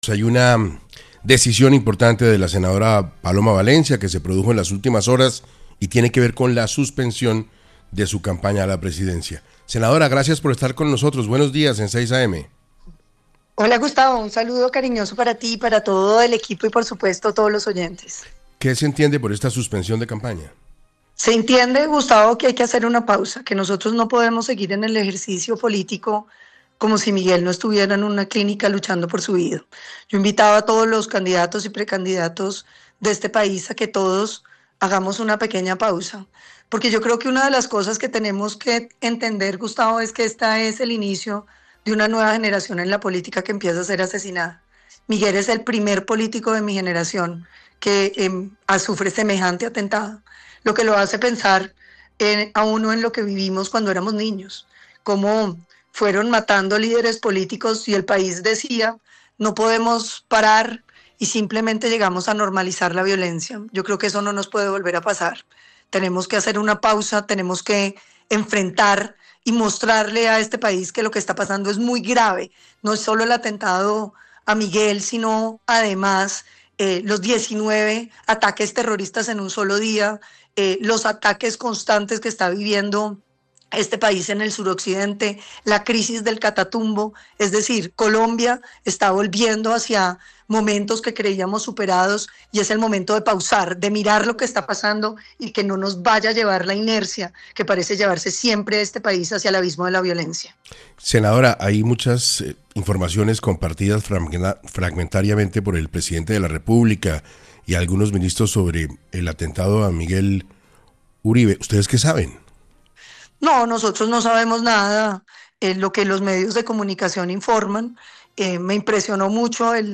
Paloma Valencia, senadora del Centro Democrático, habló en 6AM sobre la suspensión de su campaña a la presidencia.